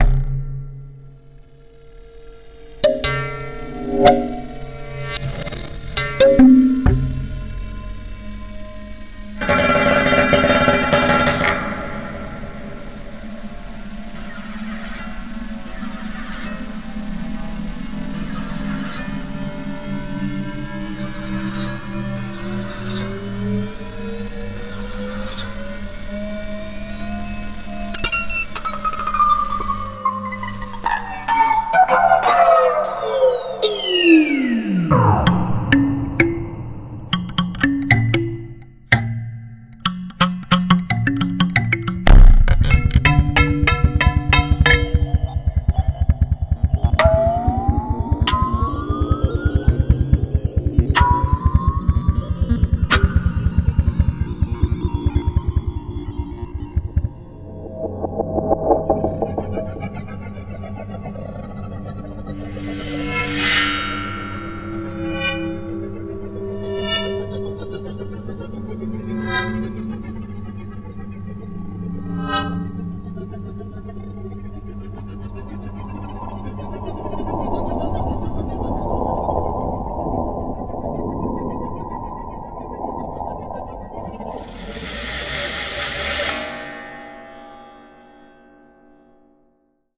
Il s'agit de ma première réalisation acousmatique qui a eu lieu dans le cadre d'un devoir.
Cette œuvre se compose donc de percussions/résonances, de rebonds, de flux, de frottements, de flexion, d'oscillation et d'accumulations de grains. Ce morceau est agencé en 3 parties, et la dernière partie reprend des motifs du premier. Malgré une matière sonore assez brute, et pour une première création, il y a selon moi de bonnes idées comme dans la première partie où un motif ascendant (flux) répond en quelque sorte à un motif descendant (succession de frottements eux-mêmes ascendants).
fracture_et_resonance.wav